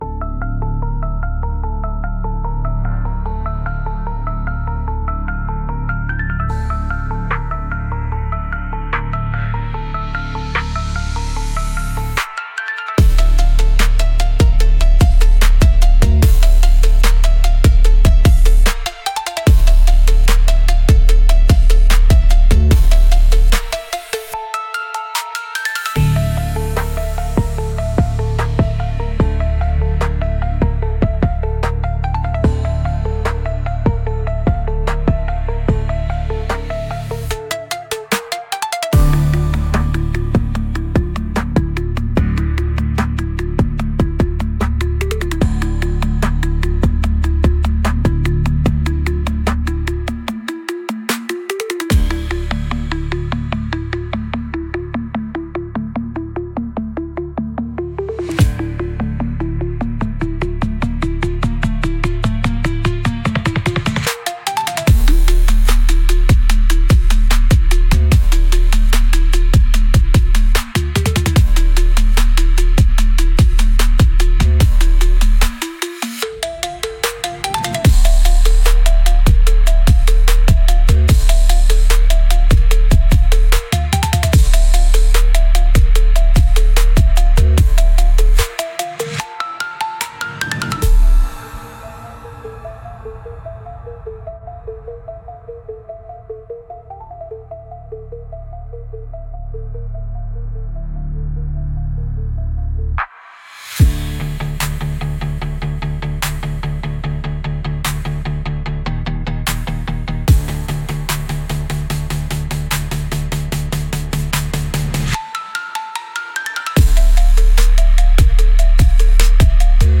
Instrumental - Reptilian Rave